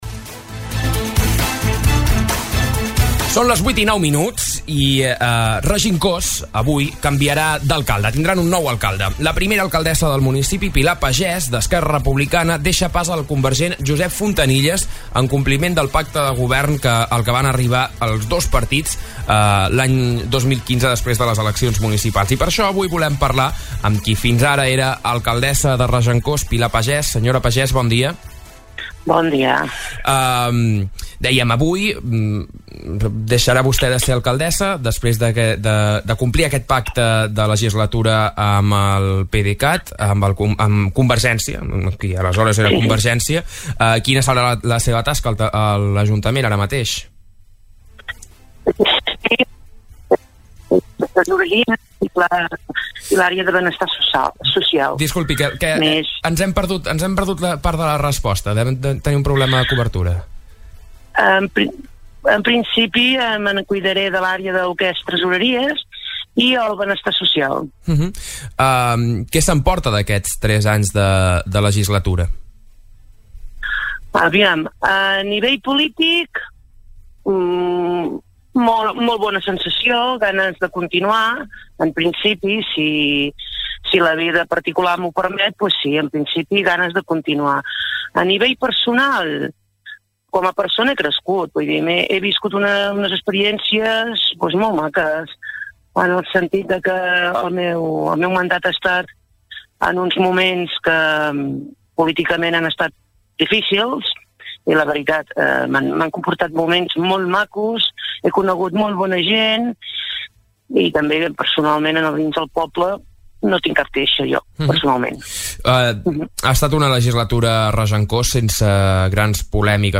Parlem amb el nou alcalde i qui fins ara ocupava aquesta posició a l'Ajuntament de Regencós sobre el futur del municipi
Aquesta setmana hem parlat amb tots dos al Supermatí de Ràdio Capital. Per començar, Pilar Pagès ens explicava les dificultats dels pobles petits per dur a terme grans accions de govern per la impossibilitat d’endeutar-se.